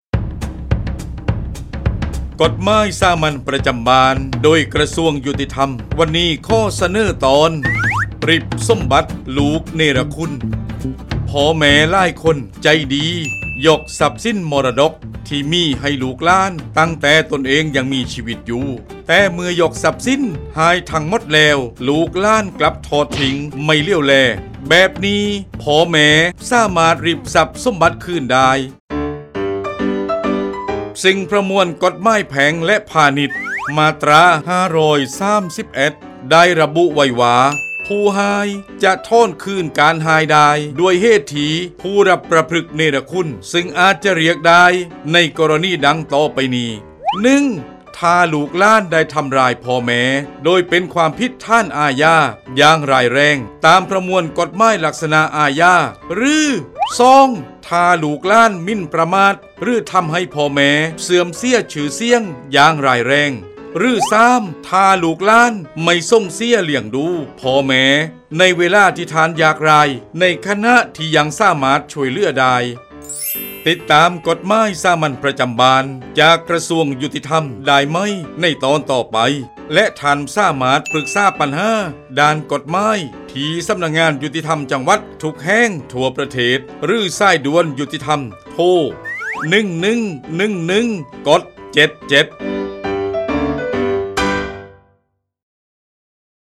กฎหมายสามัญประจำบ้าน ฉบับภาษาท้องถิ่น ภาคใต้ ตอนริบสมบัติลูกเนรคุณ
ลักษณะของสื่อ :   บรรยาย, คลิปเสียง